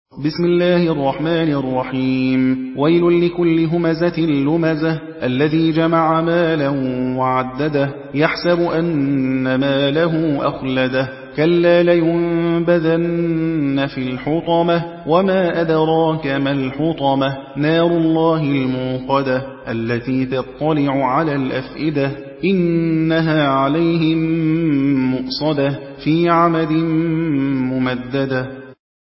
حدر